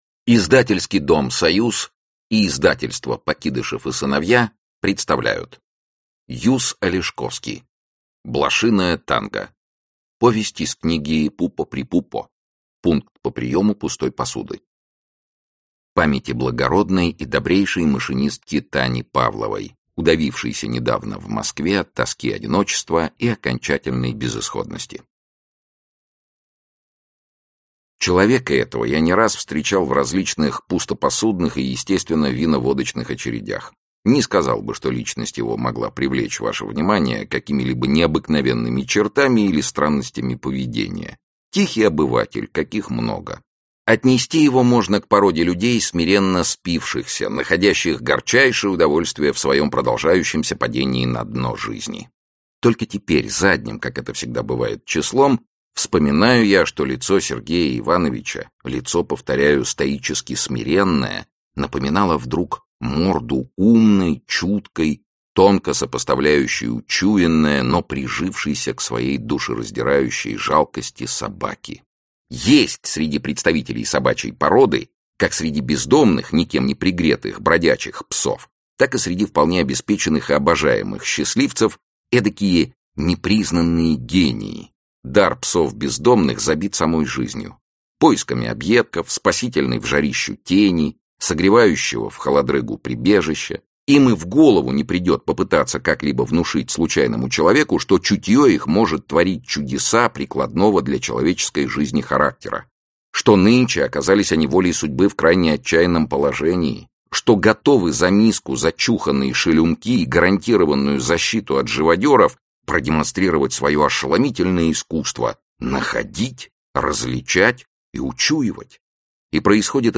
Aудиокнига Блошиное танго Автор Юз Алешковский Читает аудиокнигу Сергей Чонишвили.